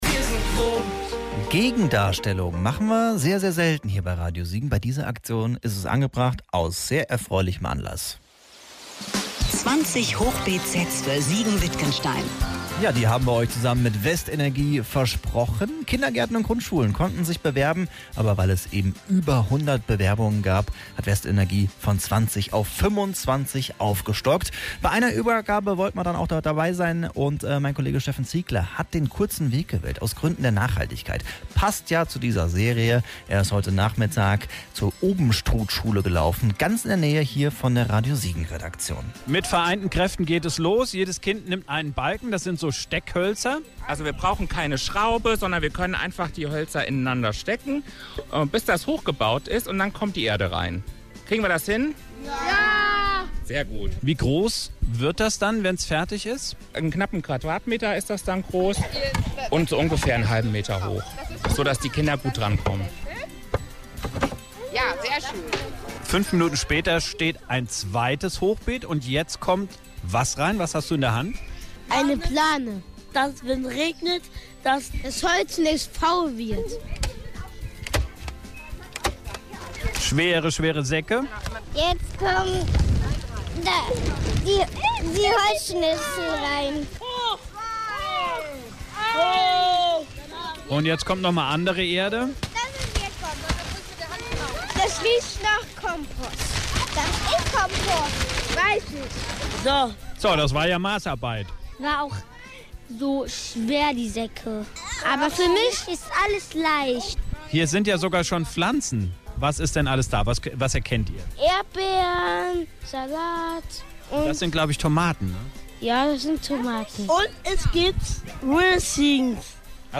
Beim Aufbau an der Obenstruthschule in Siegen waren wir dabei.
hochbeete-mitschnitt.mp3